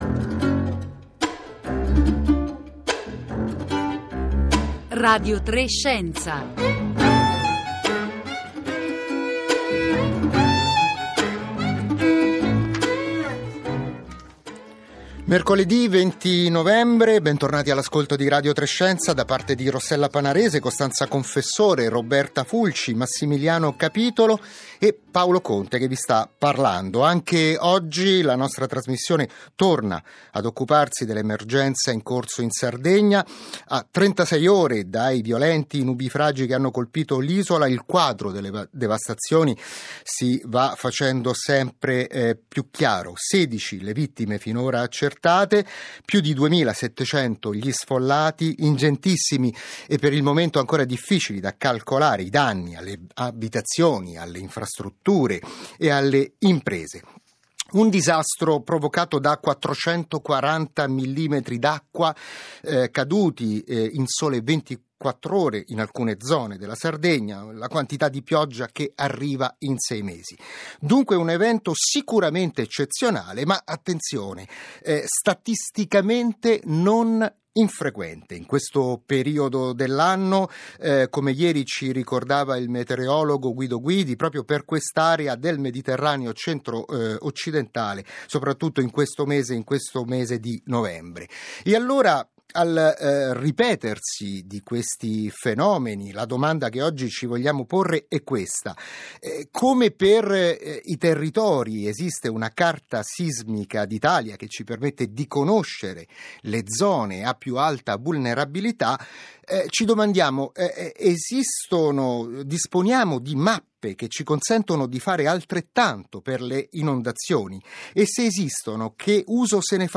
Podcast della puntata del 20 novembre di Radio3 Scienza - quotidiano scientifico della terza rete, contenente l'intervista